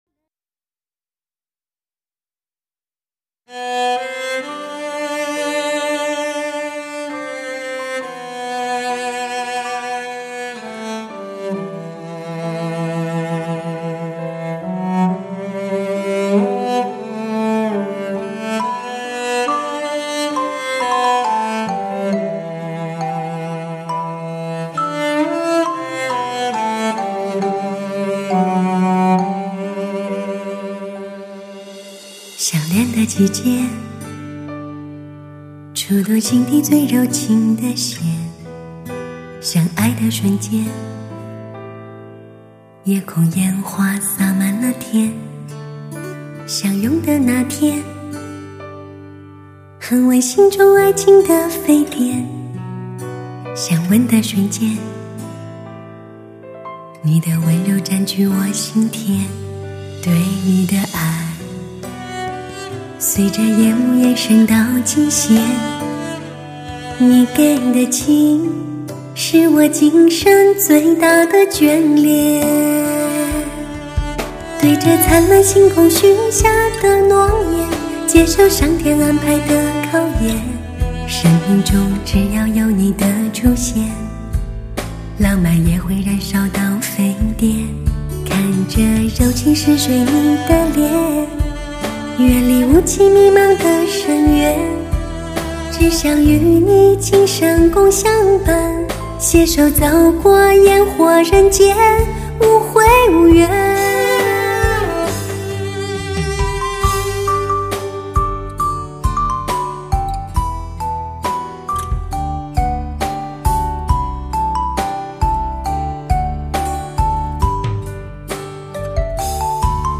史上人声最甜美 感情最丰富的女声